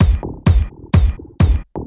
Electrohouse Loop 128 BPM (28).wav